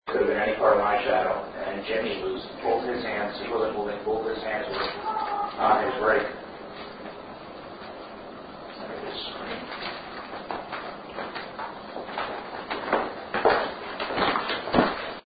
EVP Files